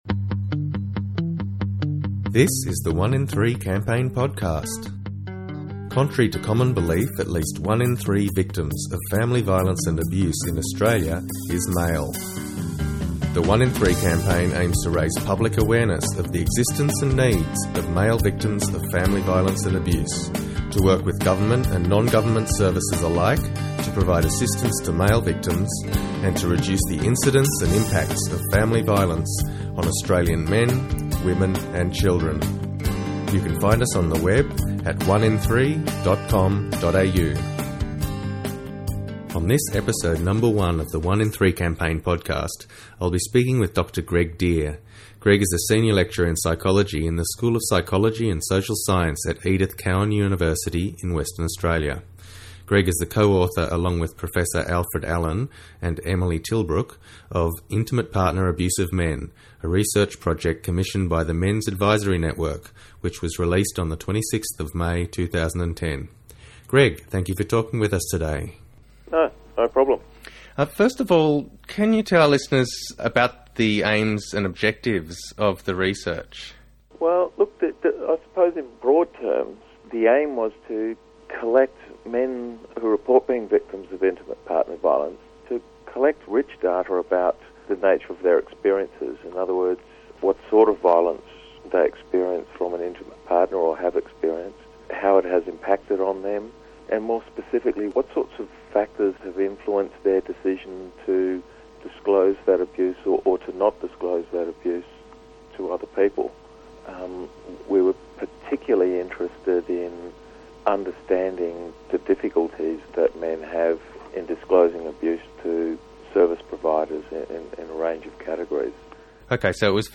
1IN3 Podcast Ep.001: Interview